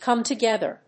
カム・トゥゲザー